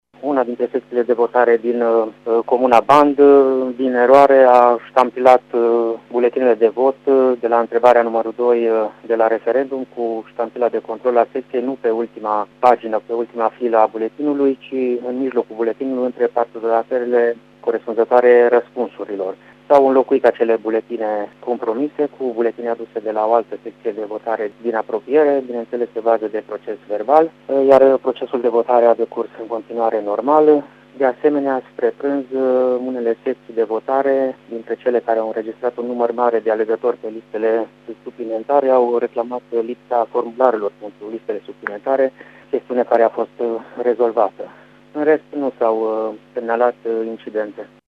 Așa a declarat, cu puțin timp în urmă, președintele BEJ Mureș, judecătorul Adrian Bereczki.